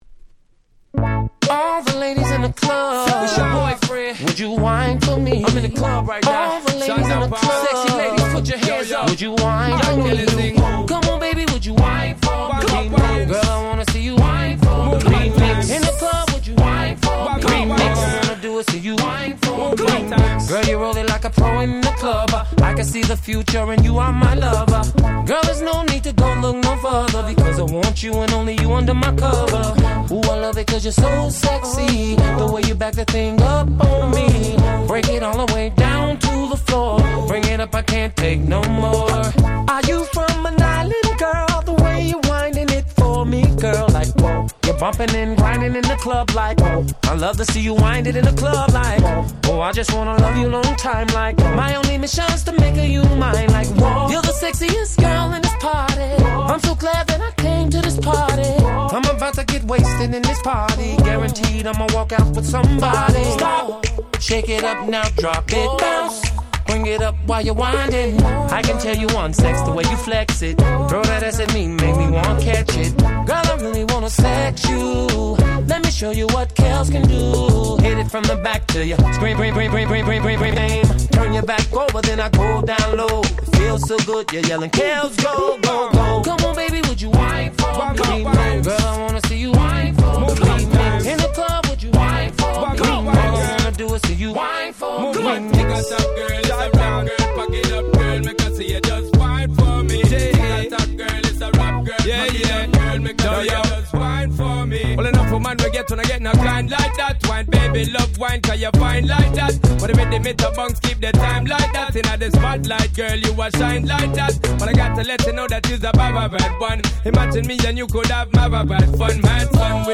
05' Smash Hit R&B / Reggaeton !!